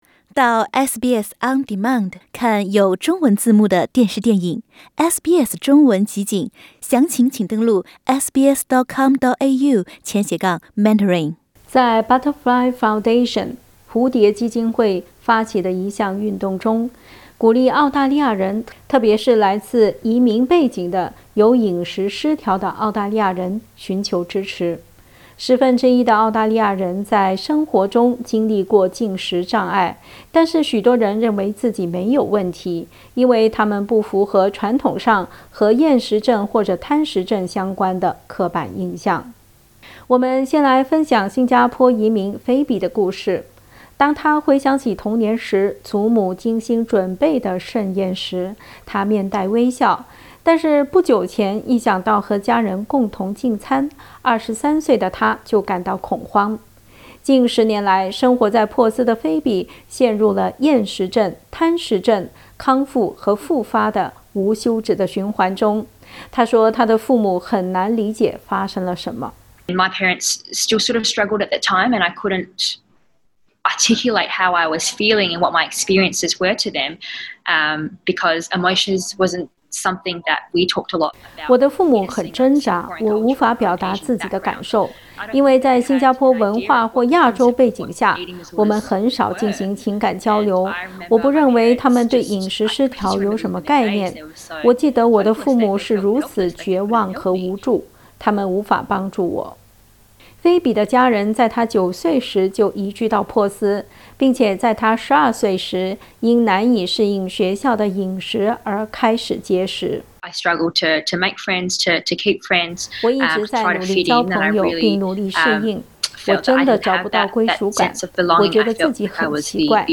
10%的澳大利亚人在生活中经历过进食障碍，相形之下移民背景人士常常不会因此去寻求应有的治疗和帮助。 点击图片收听详细报道。
两位移民背景人士、也是饮食失调症的亲历者与SBS分享了他们的病症、痛苦以及寻求帮助的过程。